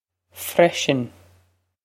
freisin fresh-in
This is an approximate phonetic pronunciation of the phrase.